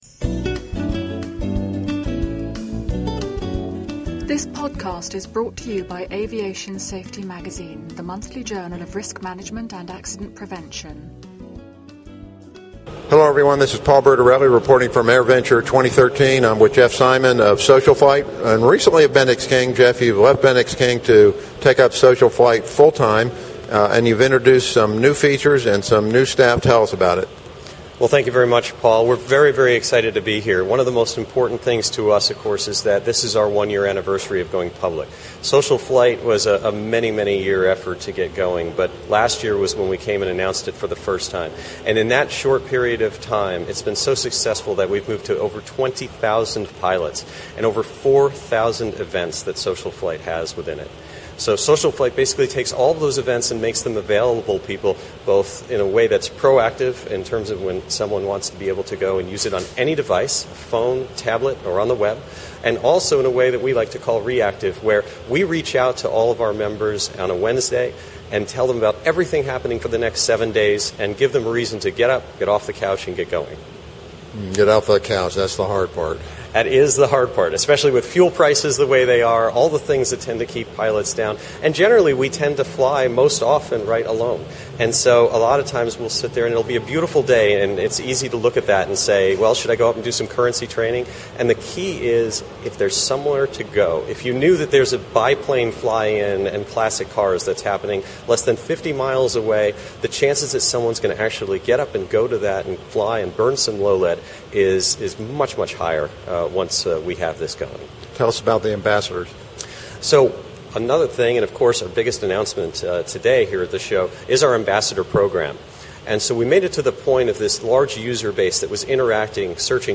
at AirVenture this year